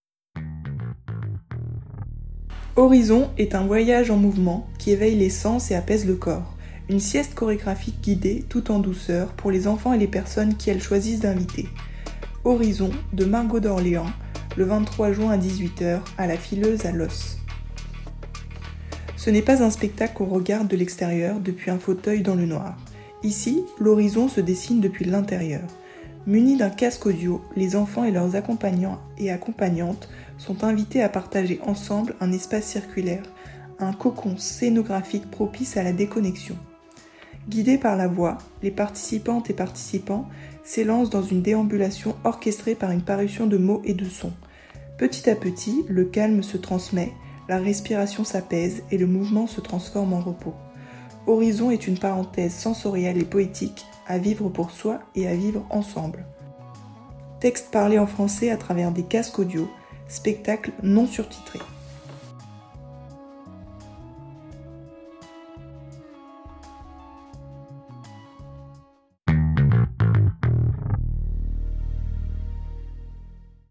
Dans un casque audio, on entend une voix qui nous guide.
La voix nous invite à faire des mouvements.
Puis elle nous guide vers le calme.
→ texte parlé en français à travers des casques audio